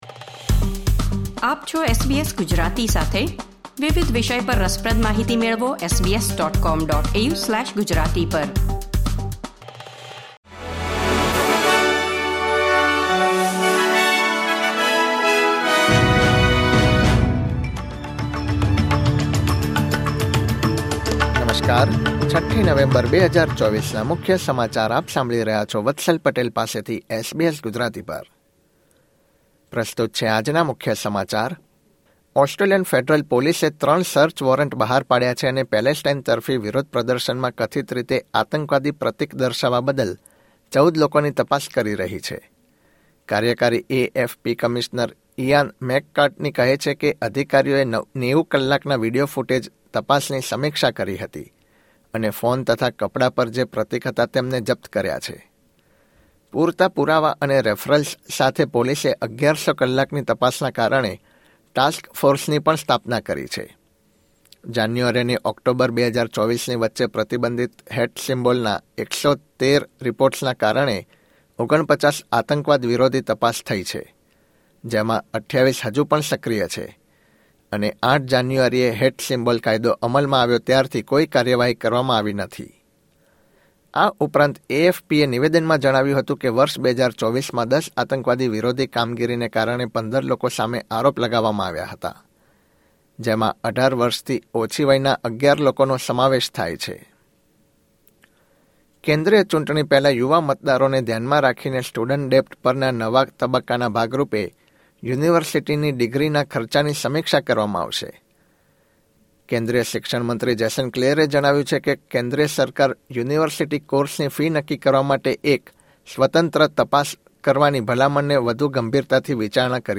SBS Gujarati News Bulletin 6 November 2024